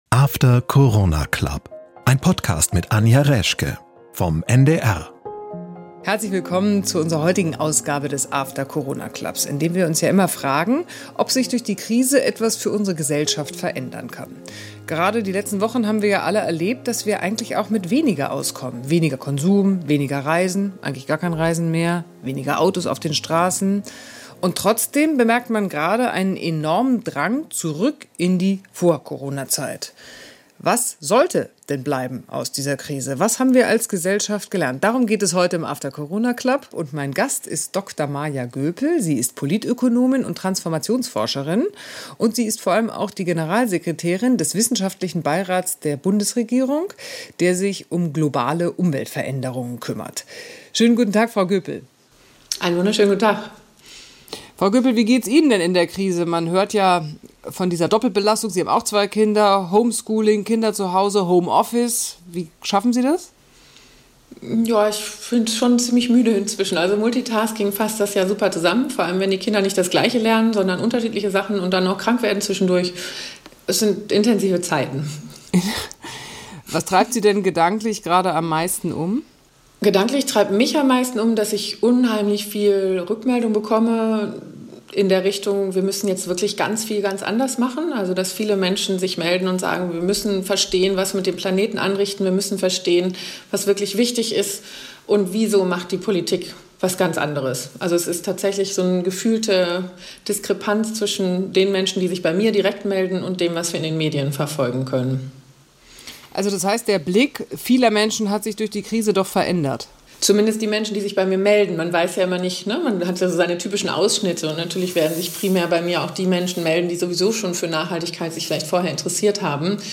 Maja Göpel ist zu Gast bei Anja Reschke im After Corona Club.